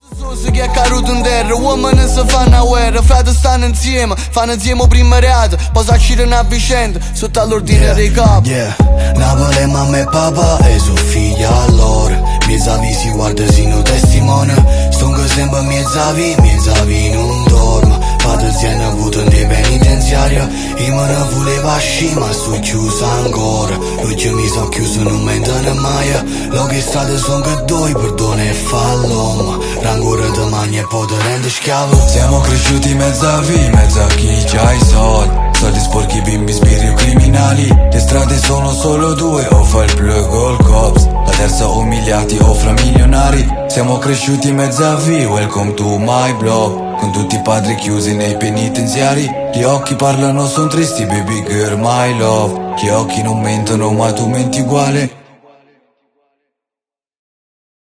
Fiat panda 1.2 scarico sportivo sound effects free download